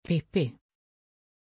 Pronunciation of Pippi
pronunciation-pippi-sv.mp3